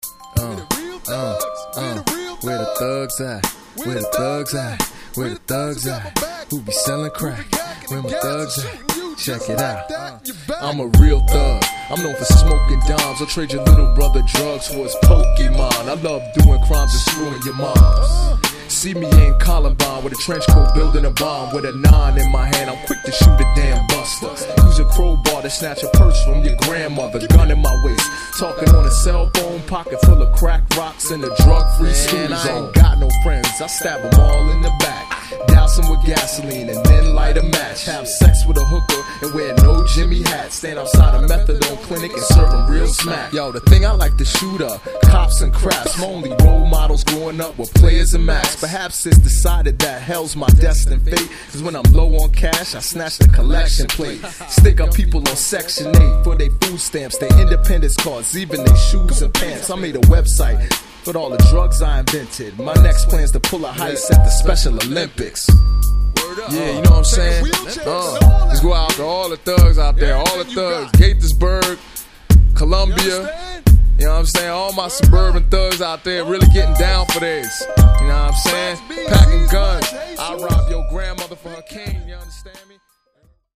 Original Music From the Soundtrack